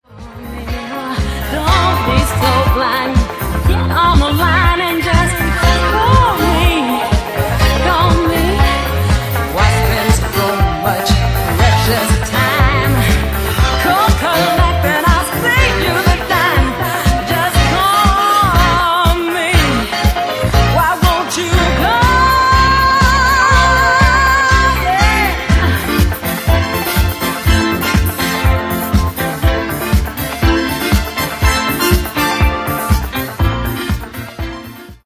Genere:   Afro | Funk